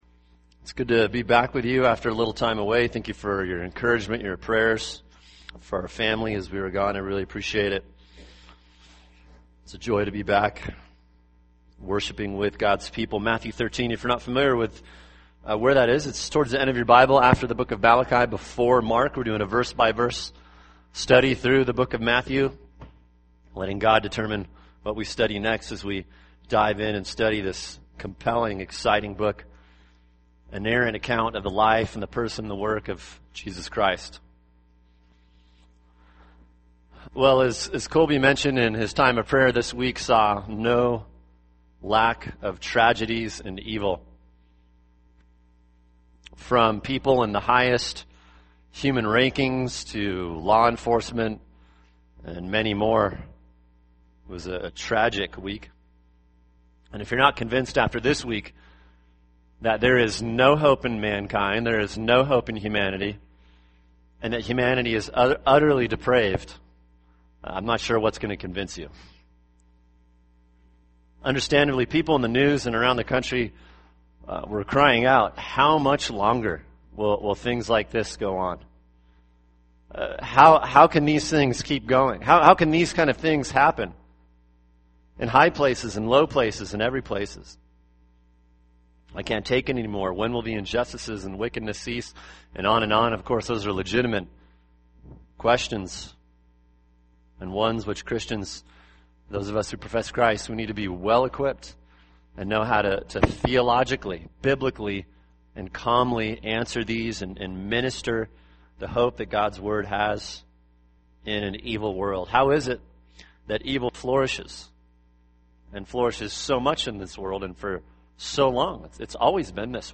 [sermon] Matthew 13:24-30, 36-43 – Christ’s Plan in an Evil World | Cornerstone Church - Jackson Hole